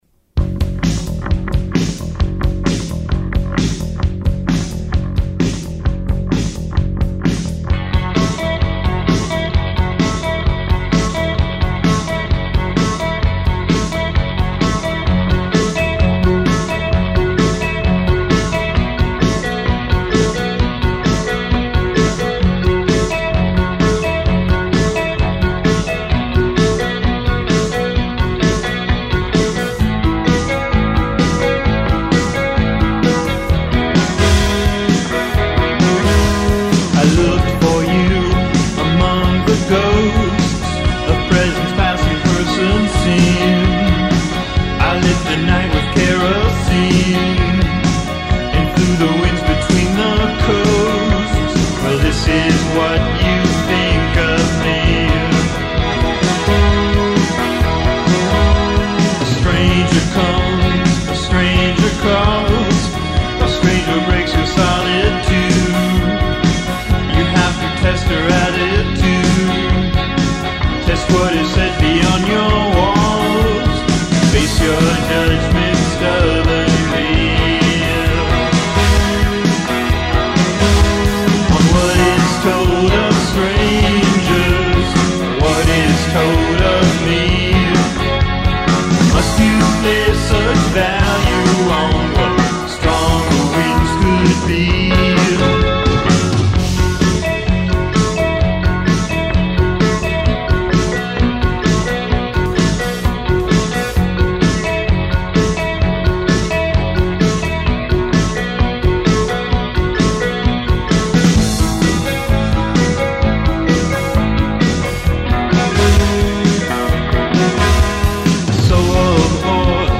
vocals & guitar
keyboards
drums
Great drum sound.